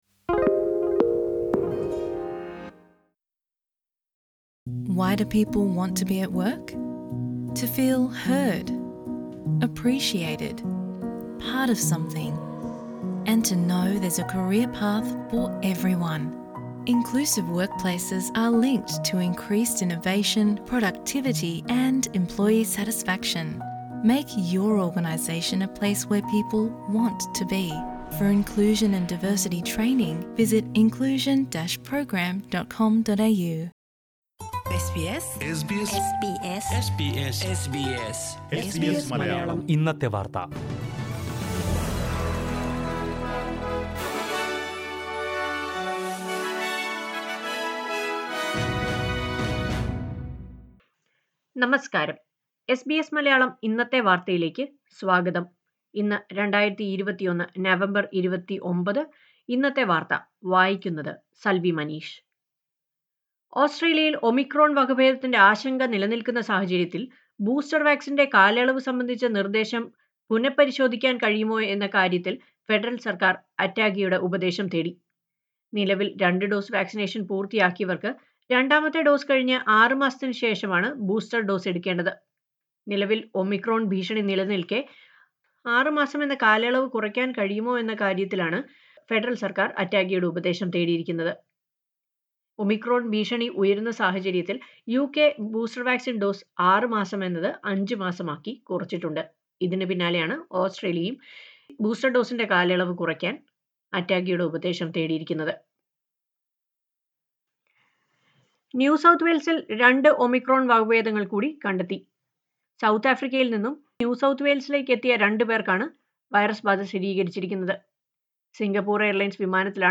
2021 നവംബർ 29ലെ ഓസ്ട്രേലിയയിലെ ഏറ്റവും പ്രധാന വാർത്തകൾ കേൾക്കാം...